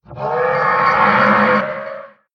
Minecraft Version Minecraft Version 1.21.5 Latest Release | Latest Snapshot 1.21.5 / assets / minecraft / sounds / mob / horse / skeleton / death.ogg Compare With Compare With Latest Release | Latest Snapshot
death.ogg